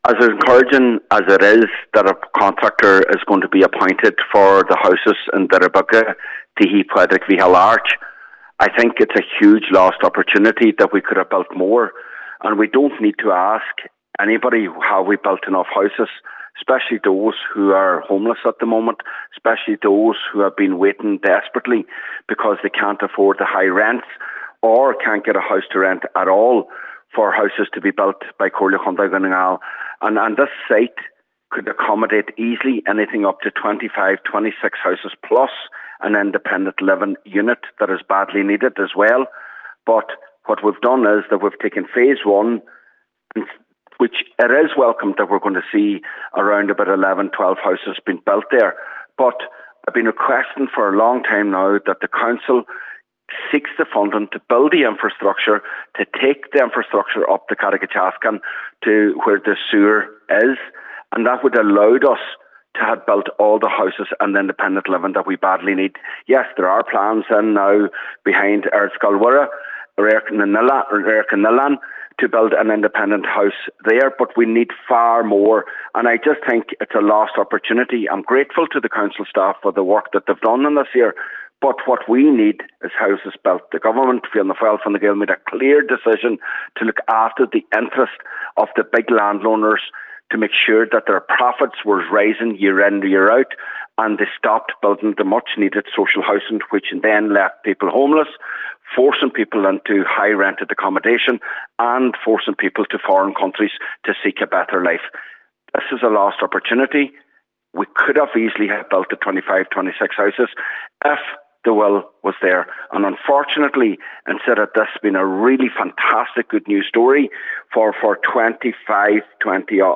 He calls the development a missed opportunity…………..